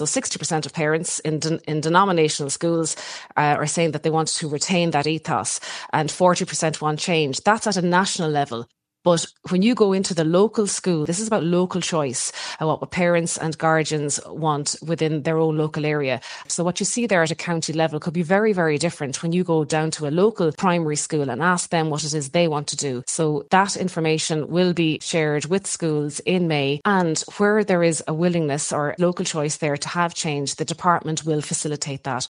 Minister Hildegarde Naughton, says communities will be supported if responses show high numbers want change………………